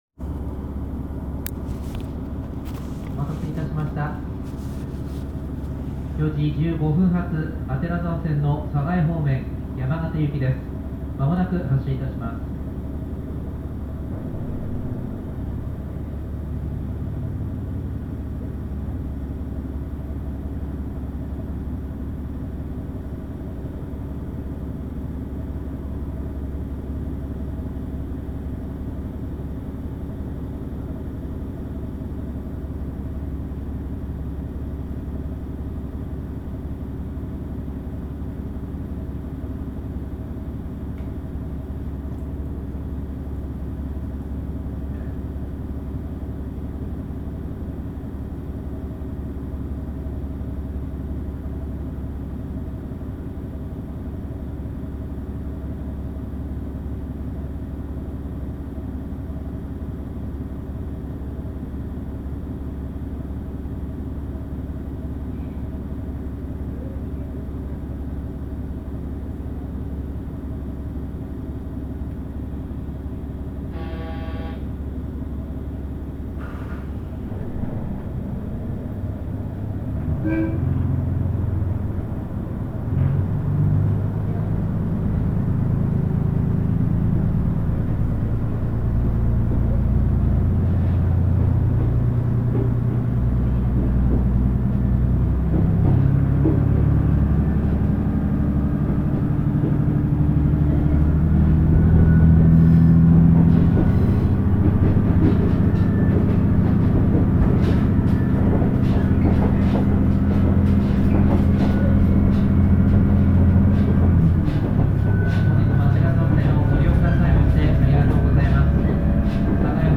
走行音
キハ100系(キハ101形)
録音区間：左沢～柴橋(お持ち帰り)